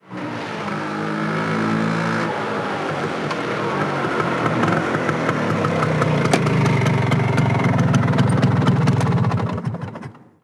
Moto marca Vespa llegando y parando
moto
Sonidos: Transportes